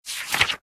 pageturn_v01.ogg